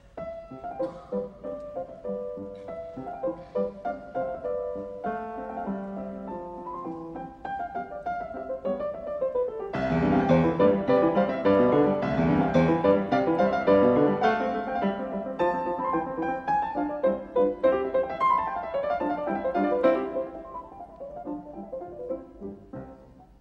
reductie van: Mozart, sonate in C-groot KV 330, laatste deel, maat 1-16
Uitgevoerd door Vladimir Horowitz.